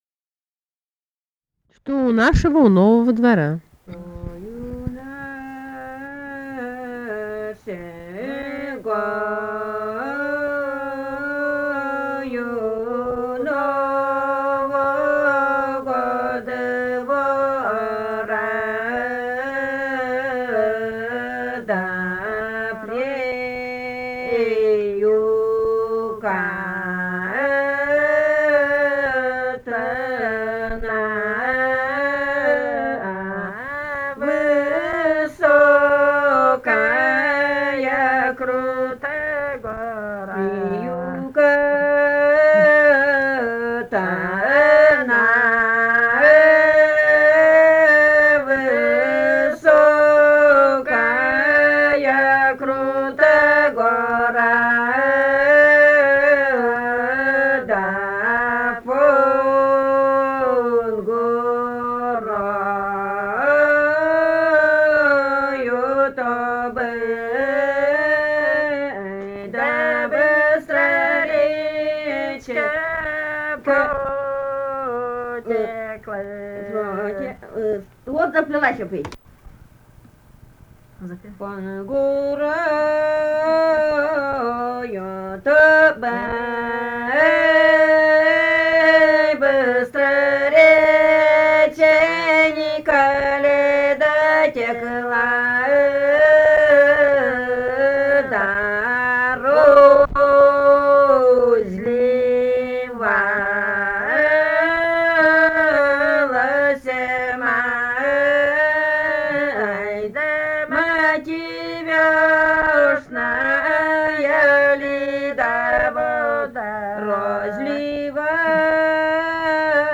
Архангельская область, с. Долгощелье Мезенского района, 1965, 1966 гг.